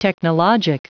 Prononciation du mot technologic en anglais (fichier audio)
Prononciation du mot : technologic